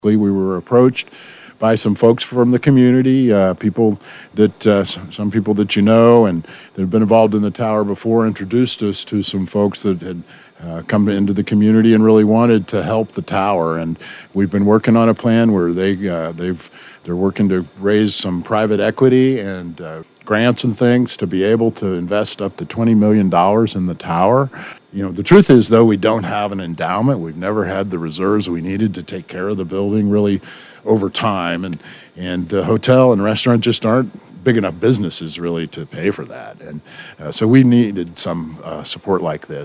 Appearing on CAR TALK on KWON on Thursday,